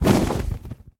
should be correct audio levels.
wings6.ogg